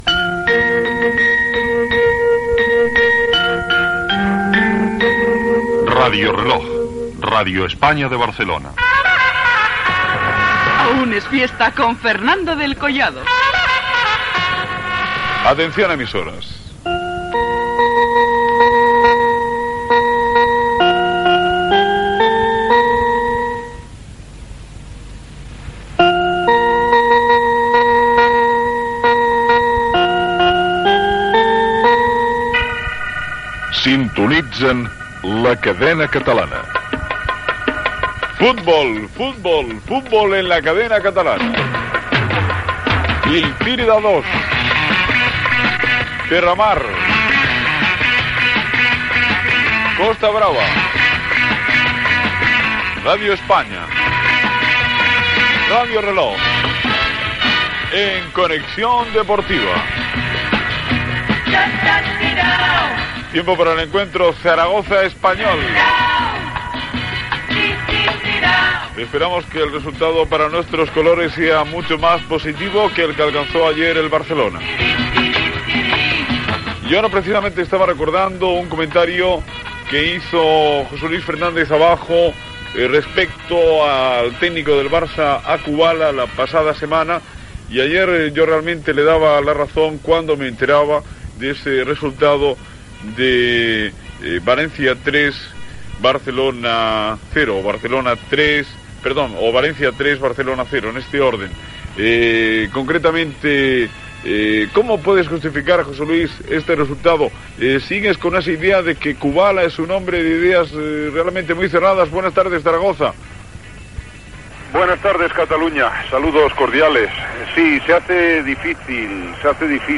Transmissió, des de Saragossa, del partit de la lliga masculina de la primera divisió de futbol entre el Zaragoza i el Real Club Deportivo Espanyol.
Indicatius de l'emissora i de la cadena, emissores connectades. Comentari sobre l'últim mal resultat del Futbol Club Barcelona, entrenat per Ladislao Kubala.
Esportiu